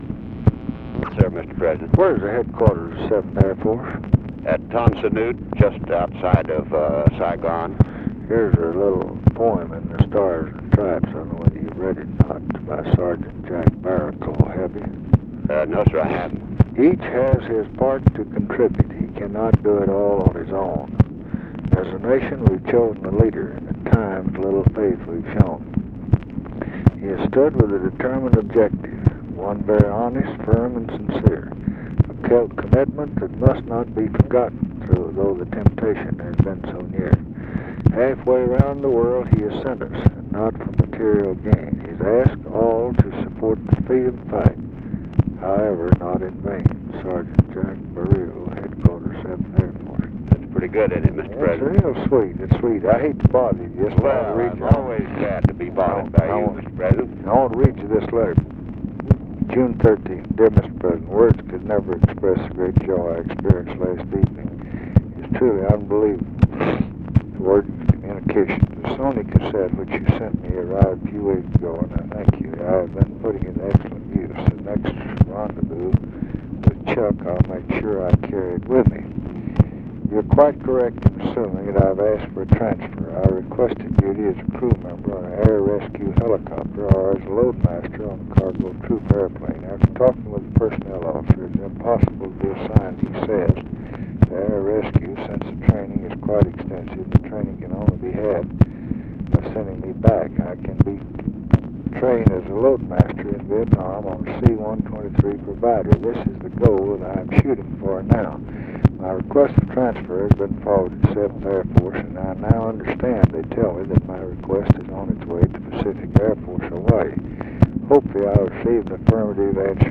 Conversation with JOHN MCCONNELL, June 20, 1968
Secret White House Tapes